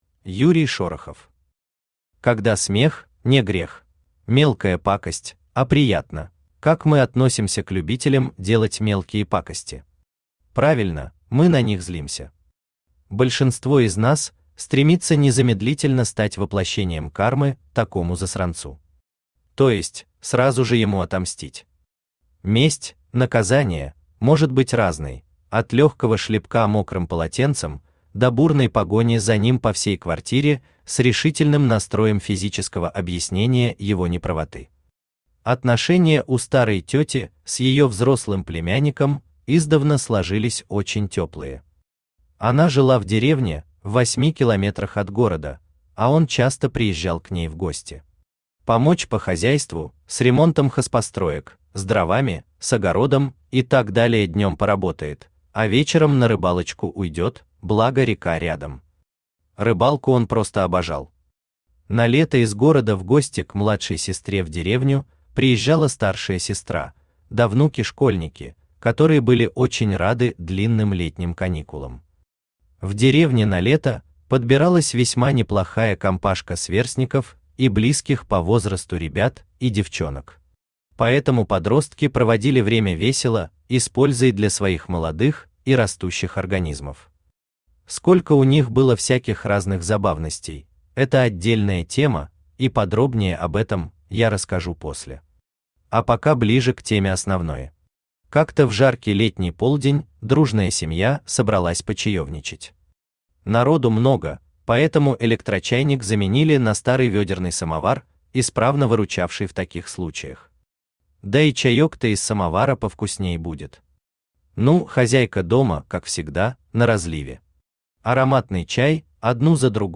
Автор Юрий Шорохов Читает аудиокнигу Авточтец ЛитРес.